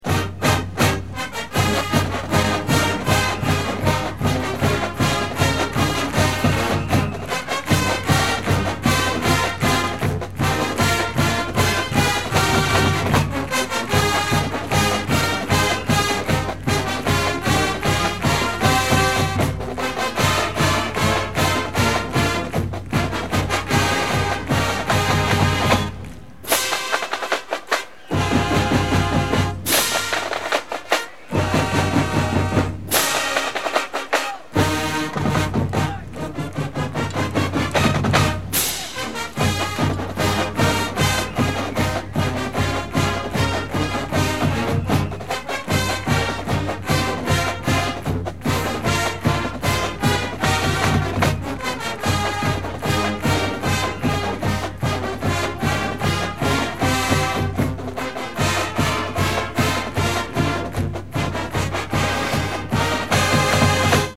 Fight Song - Mighty Oregon